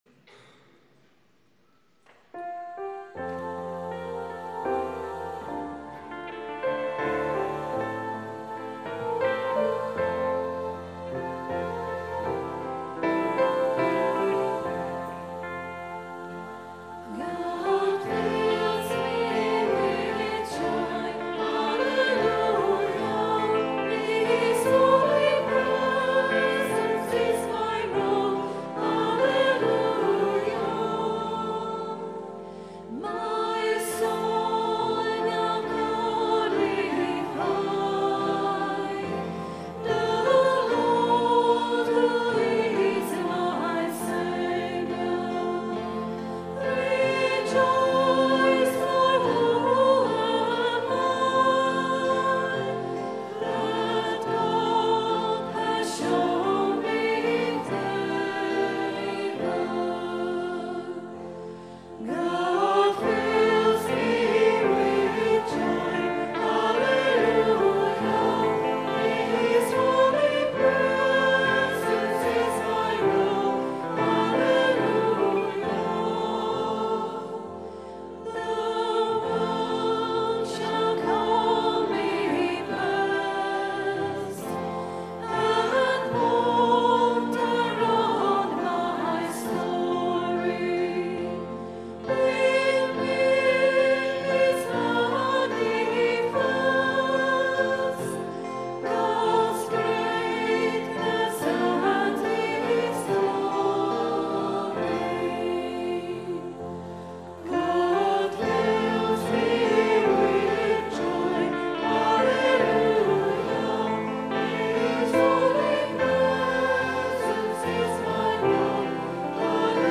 Recorded on a Zoom H4 digital stereo recorder at 10am Mass Sunday 19th December 2010.